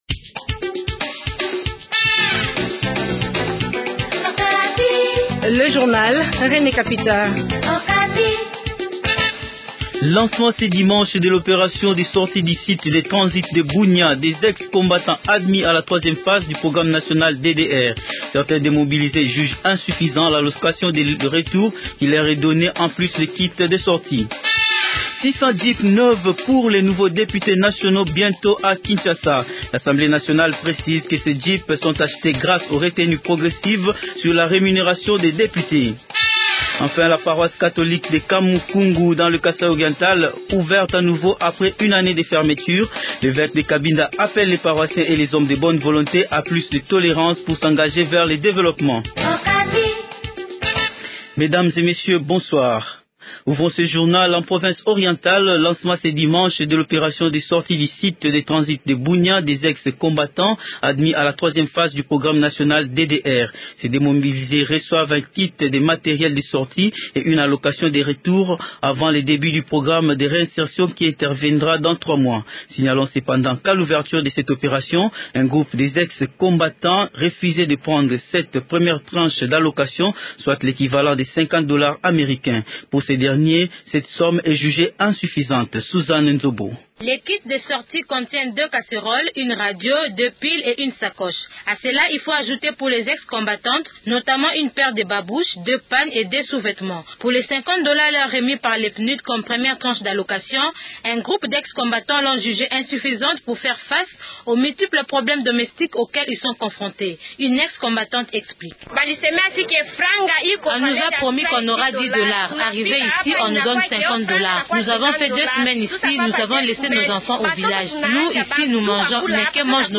Journal Francais Soir